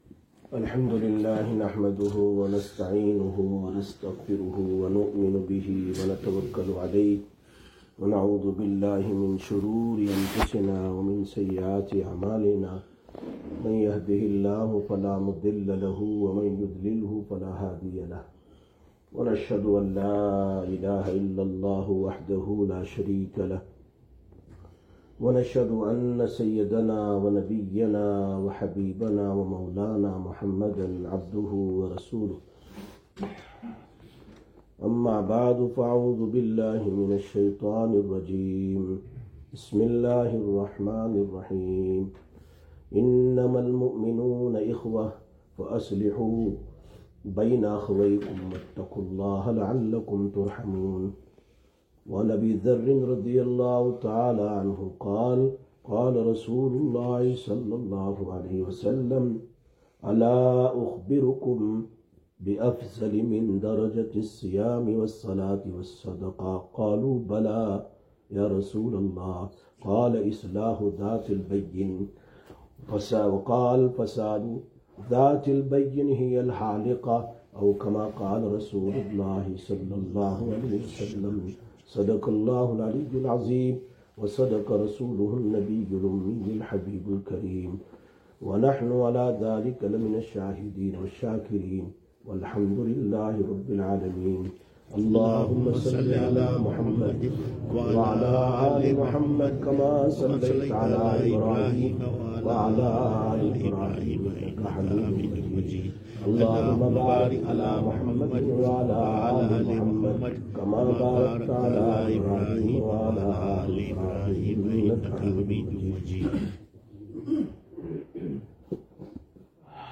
13/06/2025 Jumma Bayan, Masjid Quba